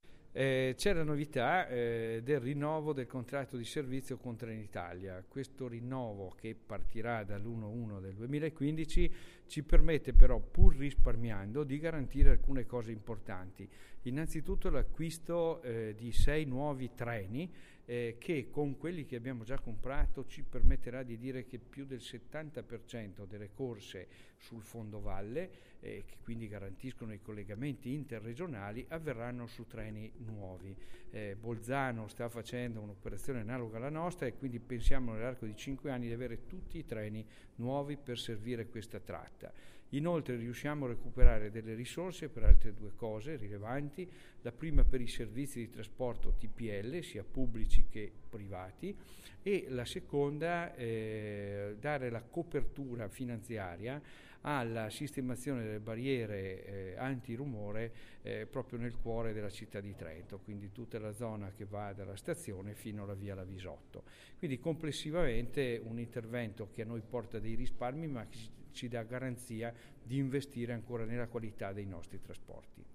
L'assessore Gilmozzi annuncia un nuovo contratto fra la Provincia e Trenitalia